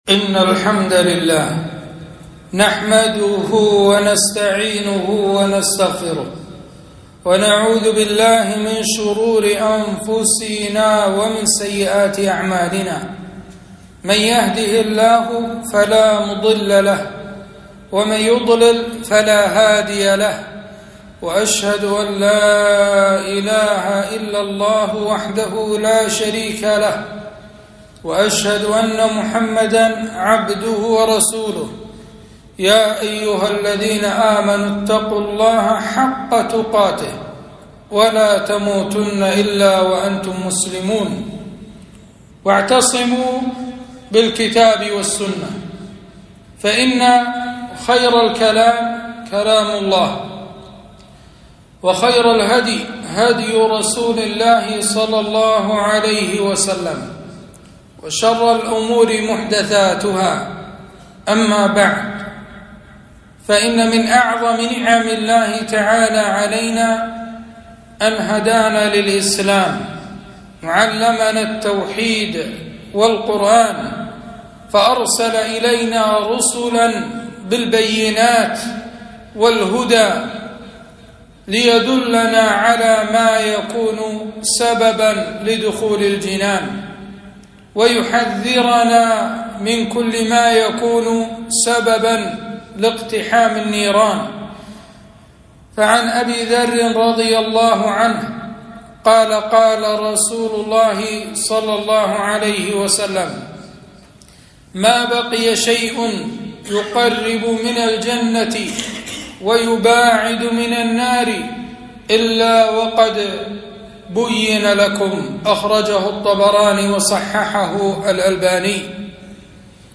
خطبة - خطر الذنوب والمعاصي